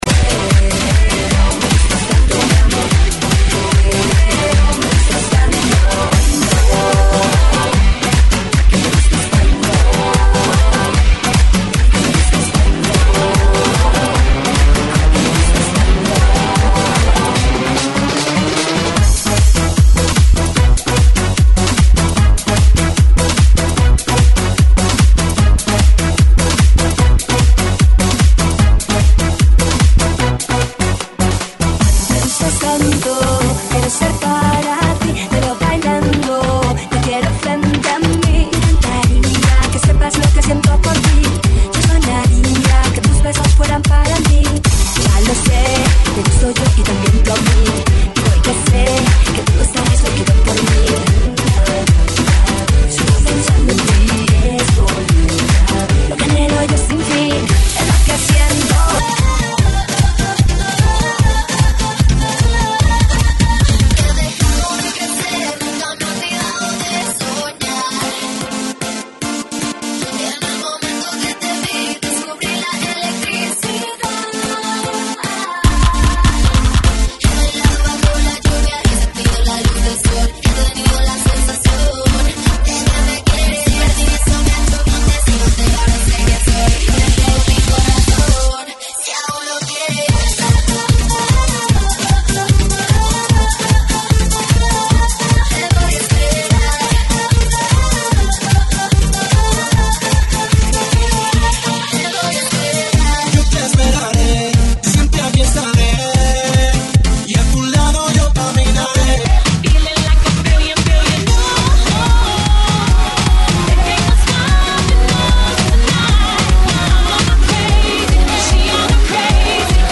GENERO: POP – ESPAÑOL – INGLES
AEROBICS (STEP-HILOW)
REMIX